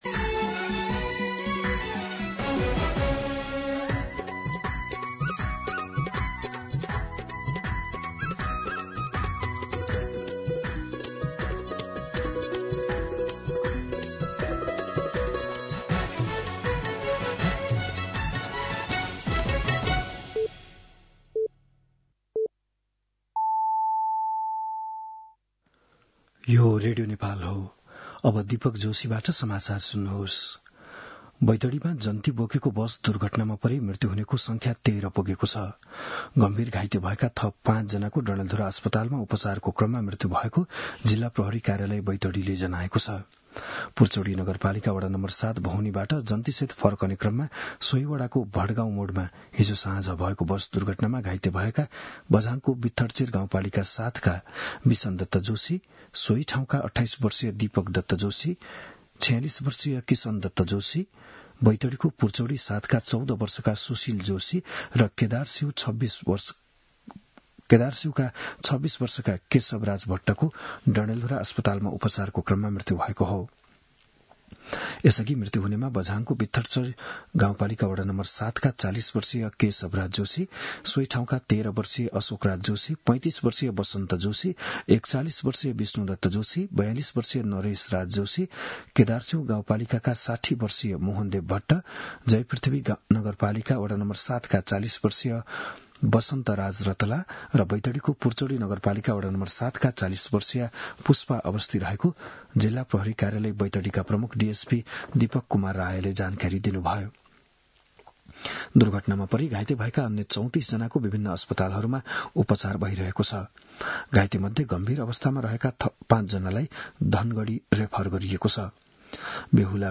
बिहान ११ बजेको नेपाली समाचार : २३ माघ , २०८२